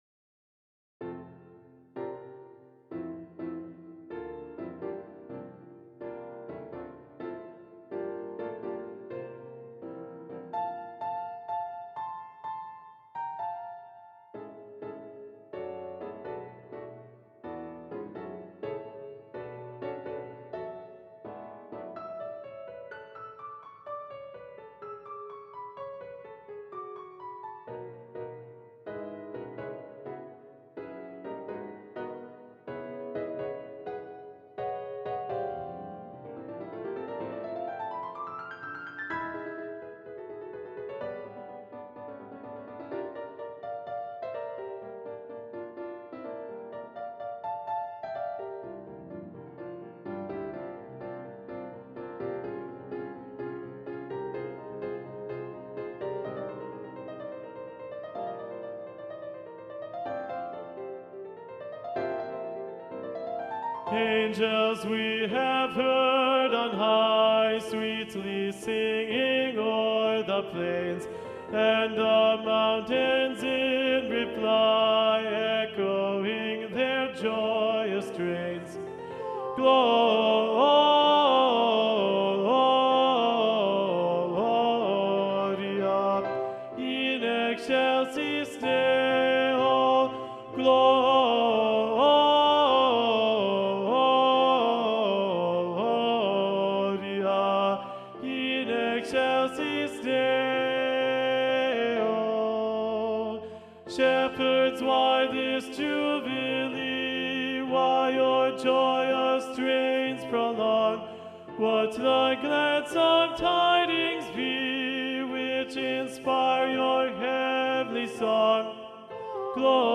Video Only: Angels We Have Heard on High - Tenor